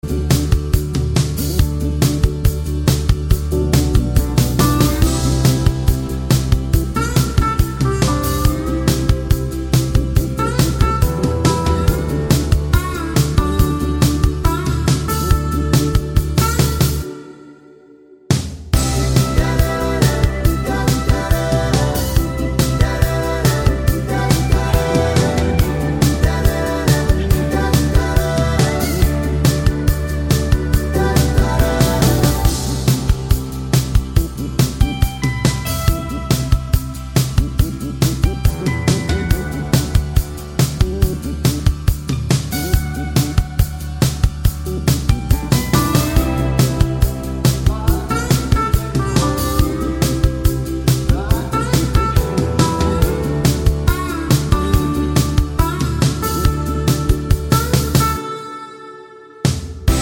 Backing Vocals Reduced Pop